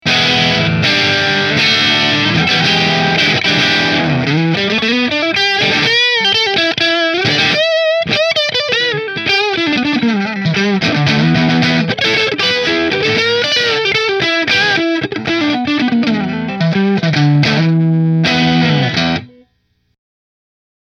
• 2 Custom Wound Humbuckers with LR Baggs Piezo System
Warrior Guitars The Dran Michael Natural Position 1 Through Marshall